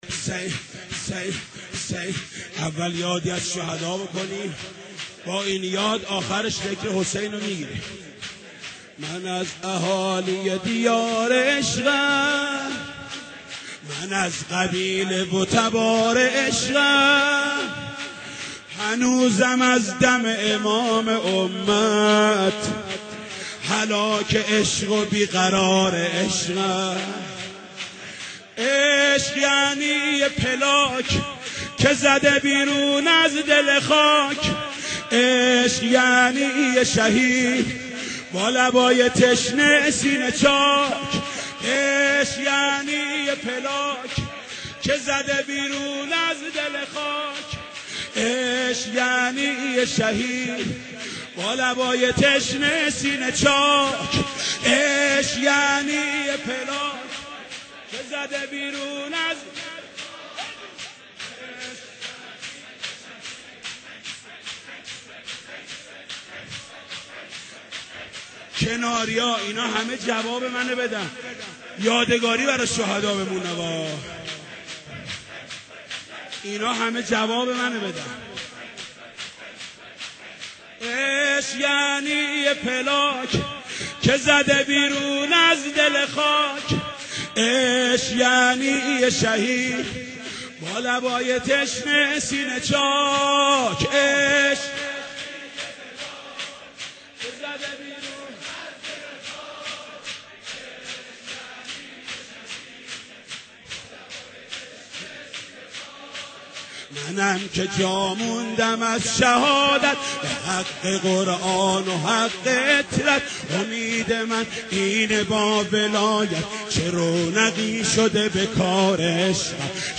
maddahi-172.mp3